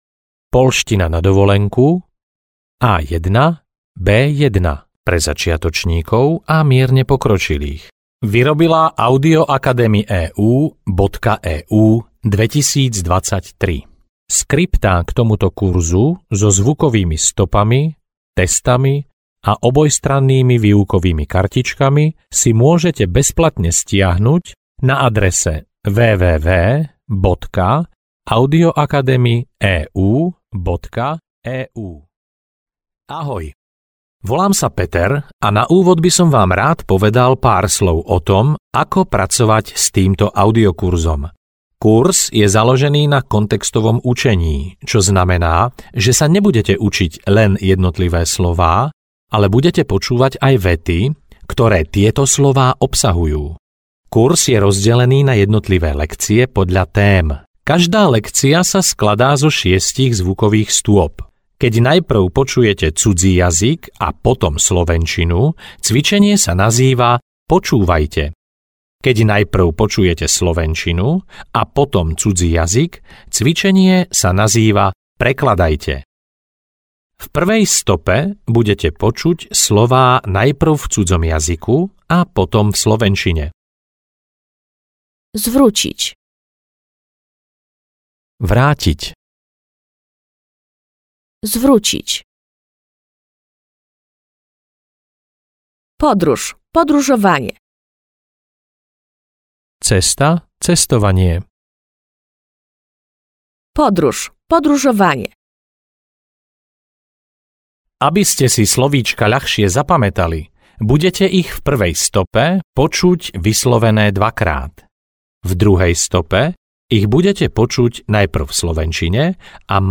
Poľština na cesty A1-B1 audiokniha
Ukázka z knihy